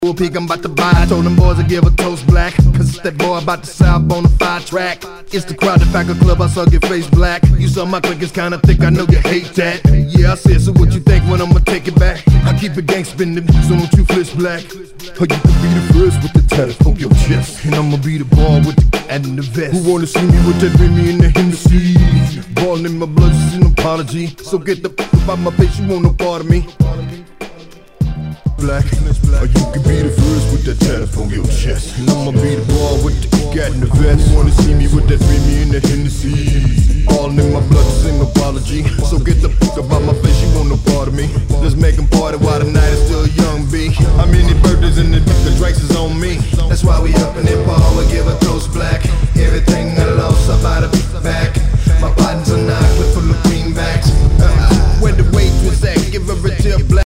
12”★Unknown / 詳細不明のヒップホップ！
HOUSE/TECHNO/ELECTRO
ナイス！詳細不明のヒップホップ！